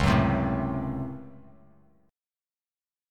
C#M7sus4#5 chord